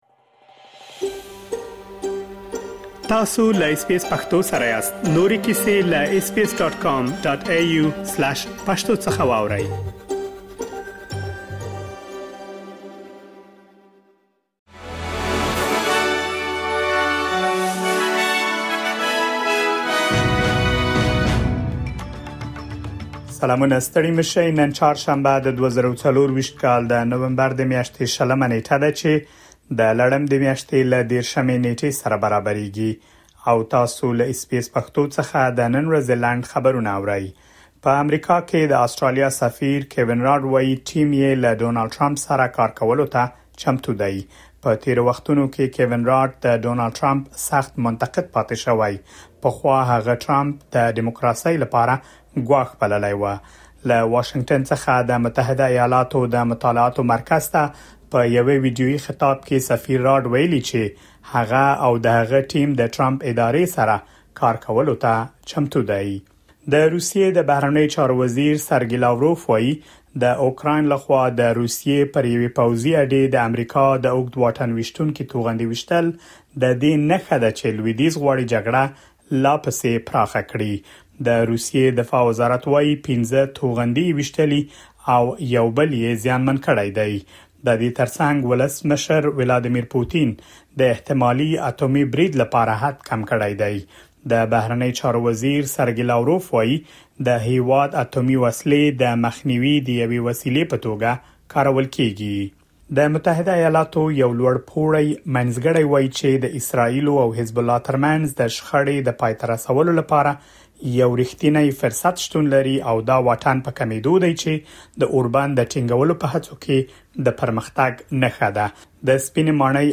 د اس بي اس پښتو د نن ورځې لنډ خبرونه |۲۰ نومبر ۲۰۲۴